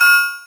Gamer World Perc 3.wav